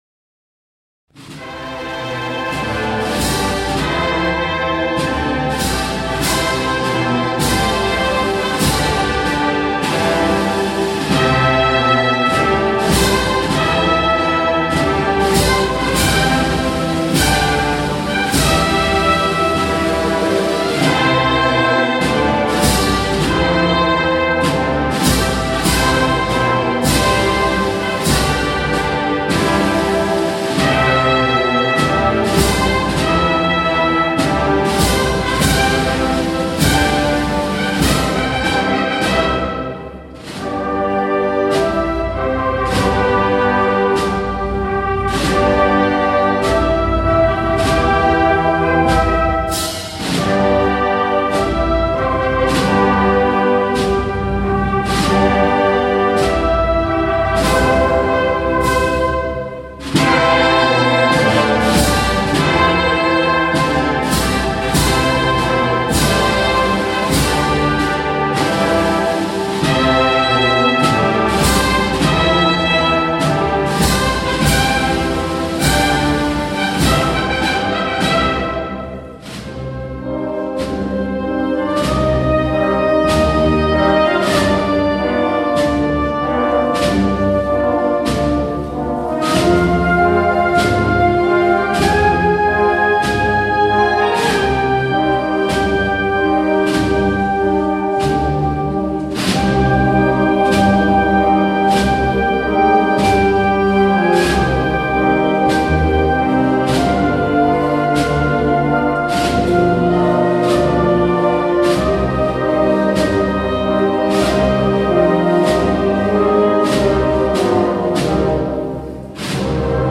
XXXIII acto de Exaltación a Nuestra Señora de la Encarnación
Sorprendió la Banda Municipal de la Puebla con la interpretación de «